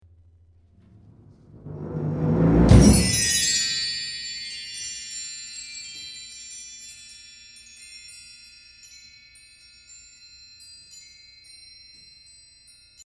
Percussion ensemble for 5 multi-percussion players.